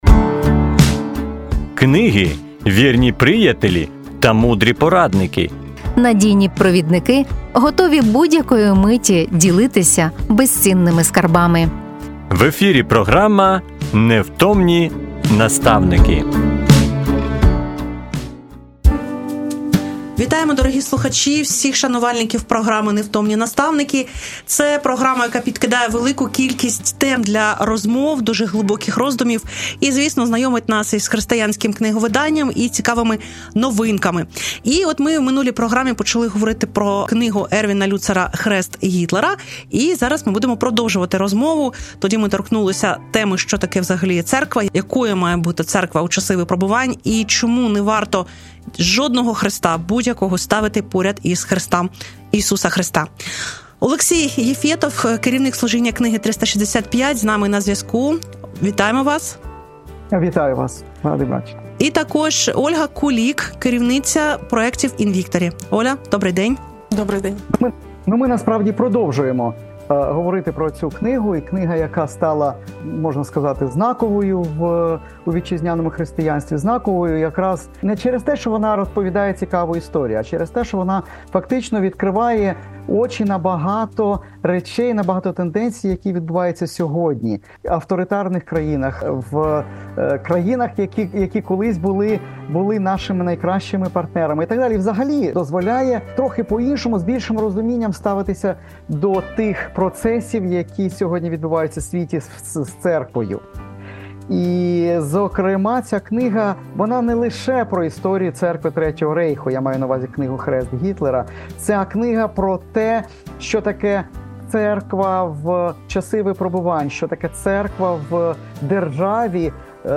Розмова за темами книги Ервіна Люцера "Хрест Гітлера".